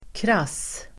Ladda ner uttalet
Uttal: [kras:]
krass.mp3